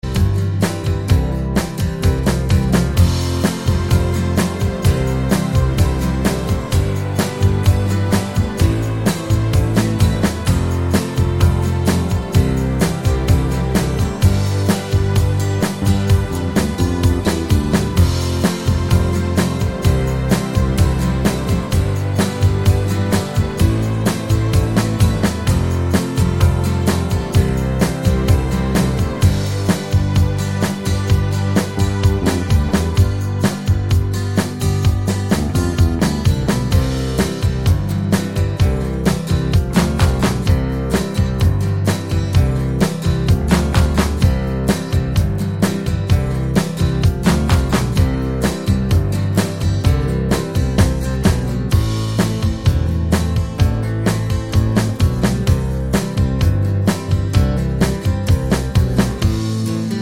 no Backing Vocals Indie / Alternative 4:03 Buy £1.50